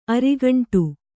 ♪ arigaṇṭu